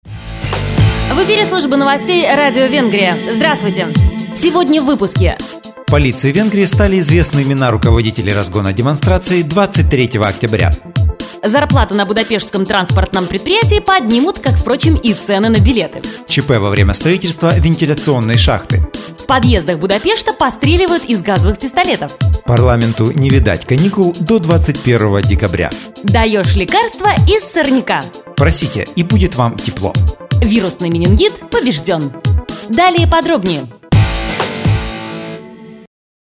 Фрагмент из новостного блока Радио "Венгрия"
2 диктора, М+Ж